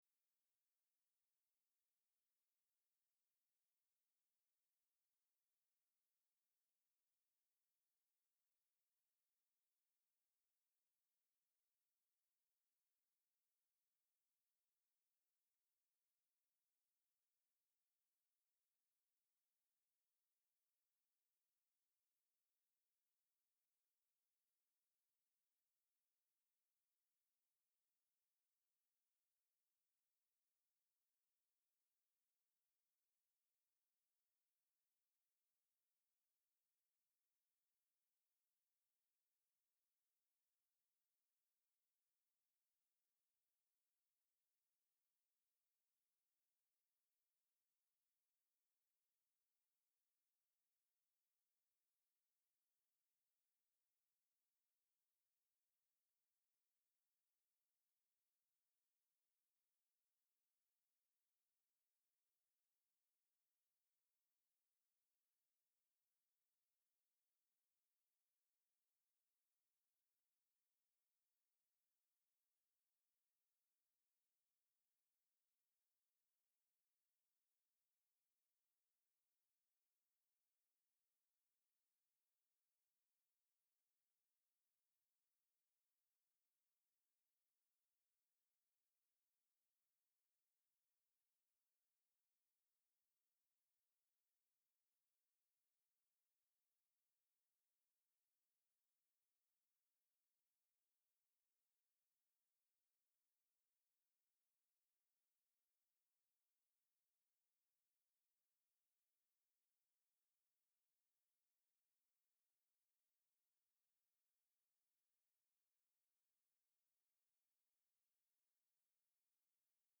1 Corinthians 13:7 Service Type: Midweek Service Bible Text